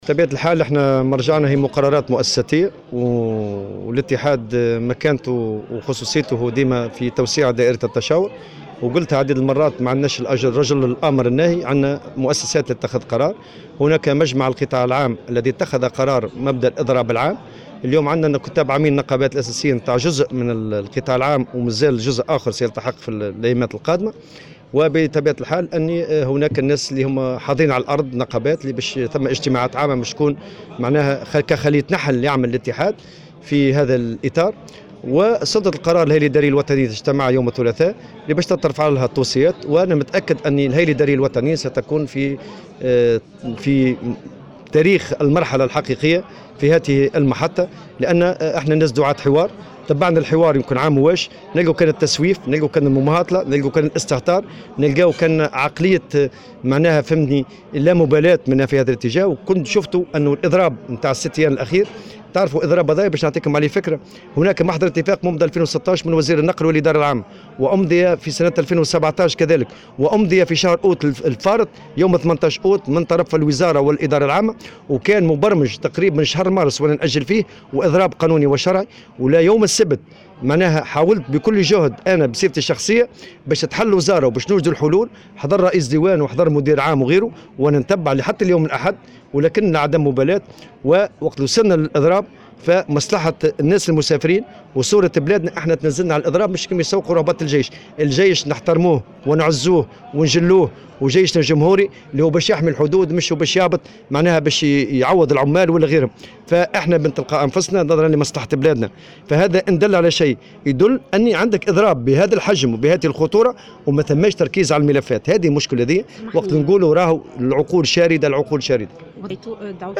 وأكد في تصريح لمراسلة "الجوهرة اف أم" أن جلّ الهياكل النقابية أيدت القرار وسترفع توصياتها إلى الهيئة الإدارية، مذكرا بقرار مجمع القطاع العام بخصوص الاضراب العام.